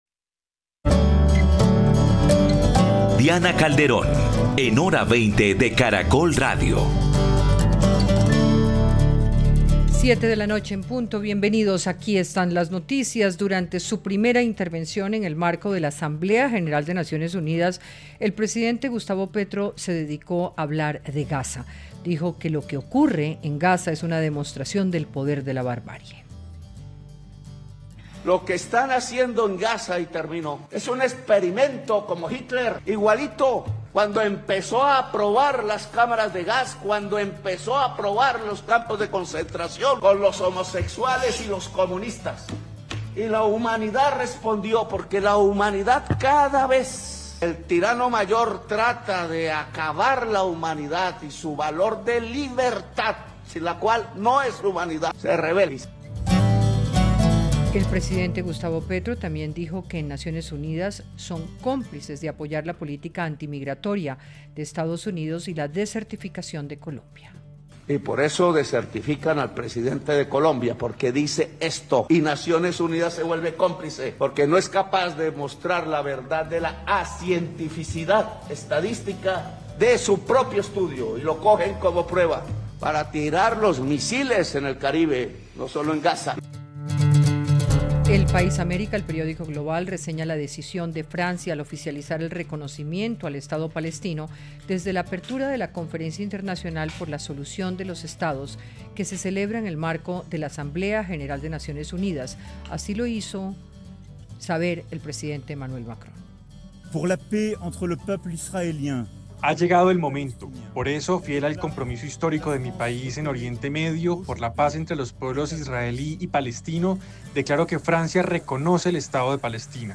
Panelistas analizaron lo que implica el reconocimiento del Estado palestino por parte de potencias, lo que viene en el discurso del presidente Petro y la realidad de las tensiones Venezuela-Estados Unidos